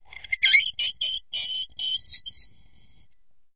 玻璃 陶瓷 " 陶瓷冰淇淋碗金属勺子在碗内发出刺耳的声音 05
描述：用金属勺刮擦陶瓷冰淇淋碗的内部。 用Tascam DR40录制。
Tag: 刮下 金属勺 尖叫 刮去 勺子 尖叫 陶瓷 金属